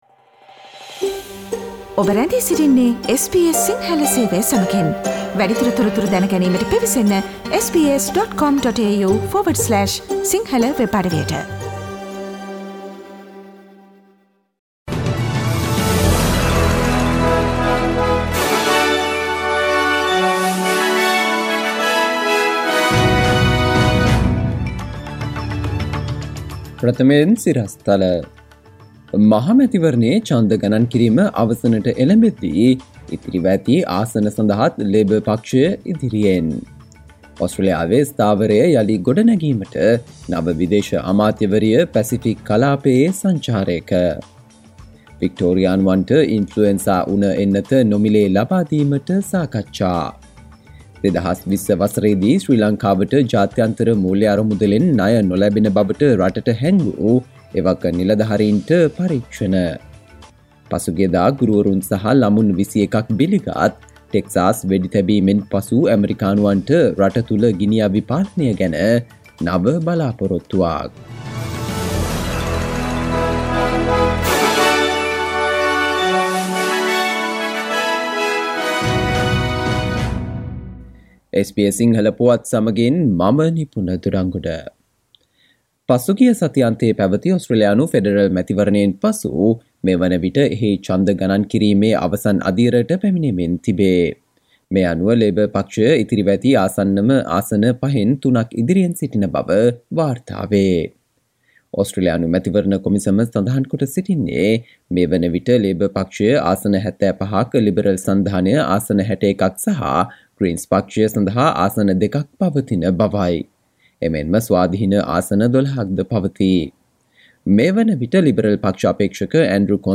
සවන්දෙන්න 2022 මැයි 26 වන බ්‍රහස්පතින්දා SBS සිංහල ගුවන්විදුලියේ ප්‍රවෘත්ති ප්‍රකාශයට...